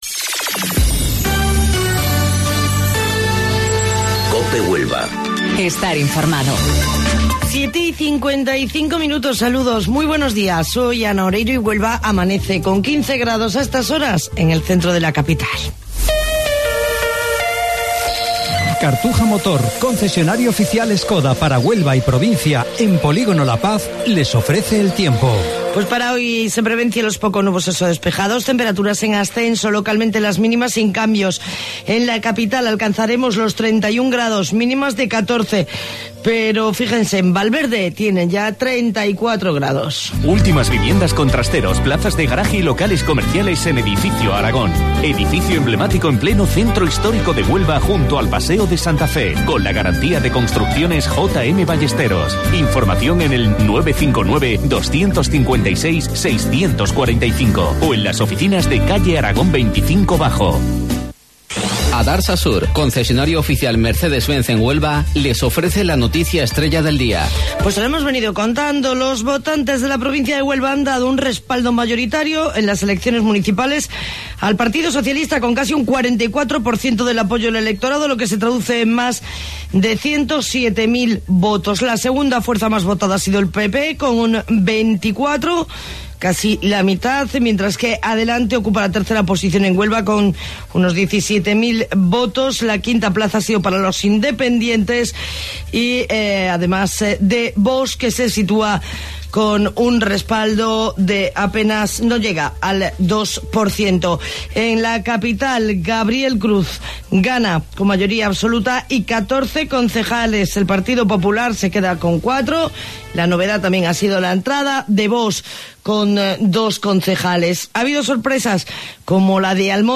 AUDIO: Informativo Local 07:55 del 27 de Mayo